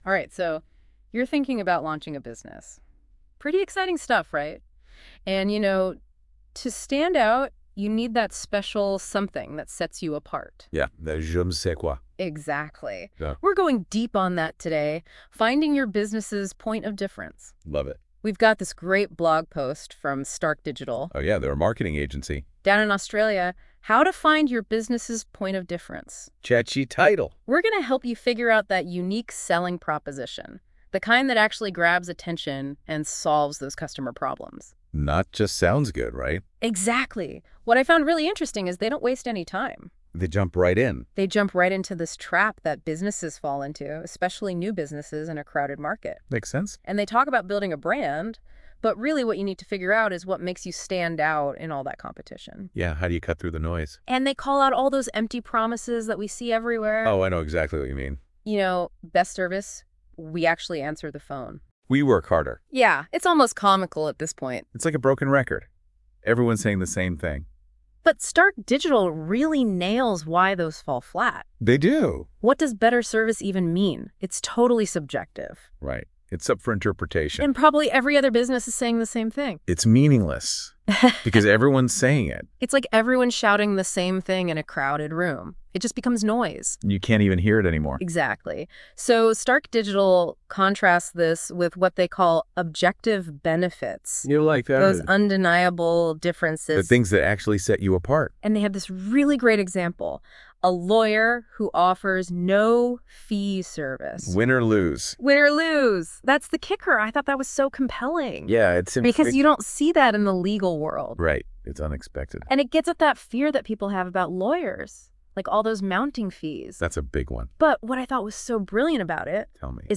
Don’t want to read? Listen to the AI audio summary here!